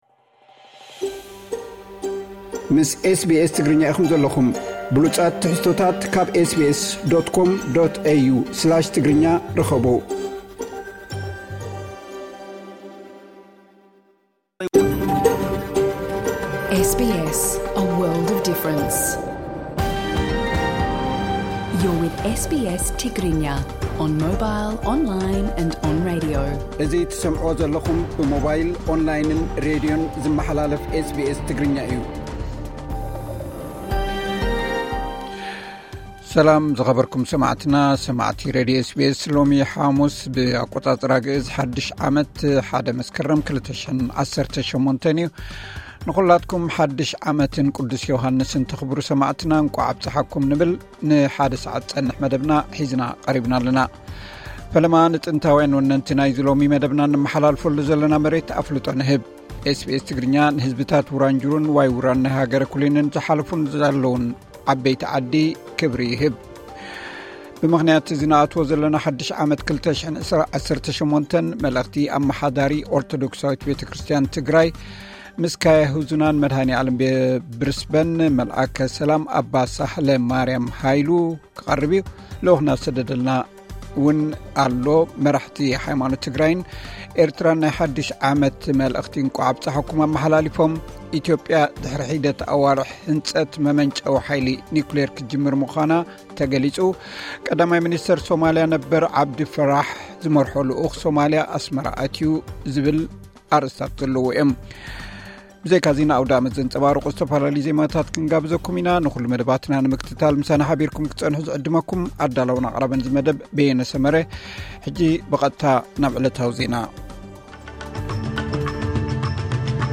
ብዘይካዚ ን ኣውደኣመት ዘንጸባርቑ ዝተፈላለዩ ዜማታት ክንጋብዘኩም ኢና።